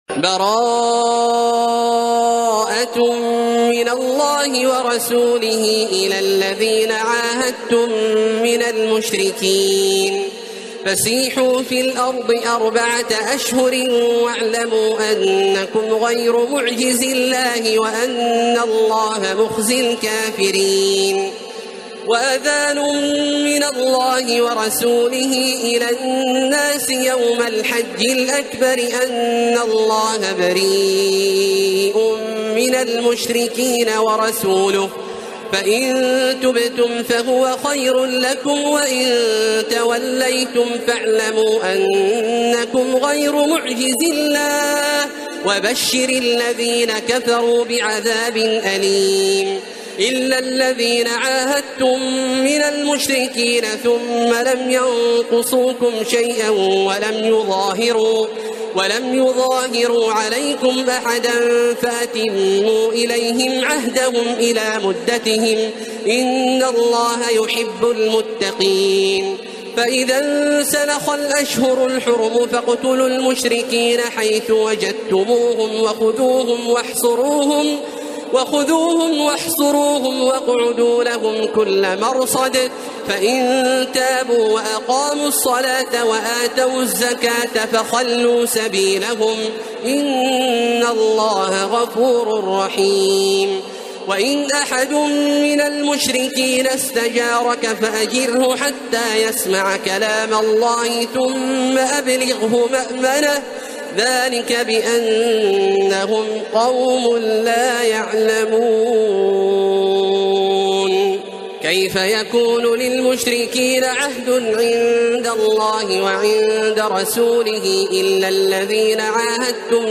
سورة التوبة Surat At-Taubah > مصحف الشيخ عبدالله الجهني من الحرم المكي > المصحف - تلاوات الحرمين